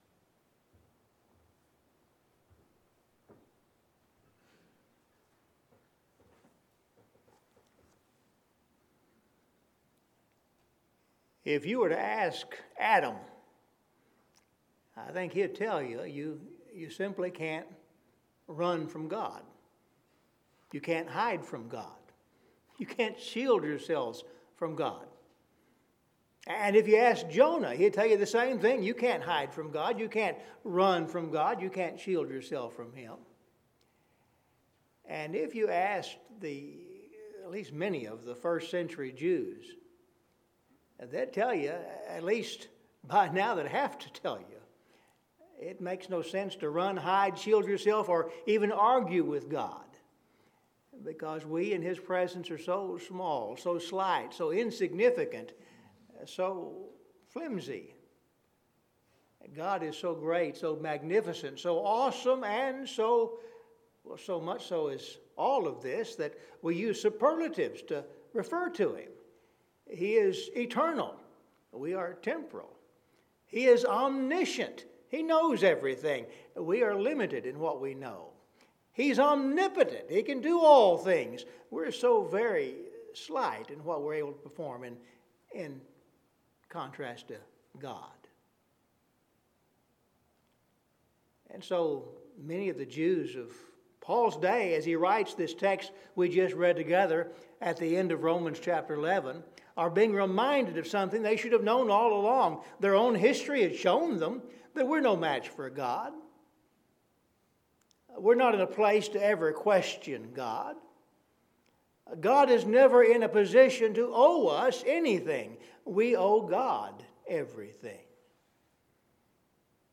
Scripture Reading – Romans 11:33-36